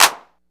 DB - Claps & Snares (35).wav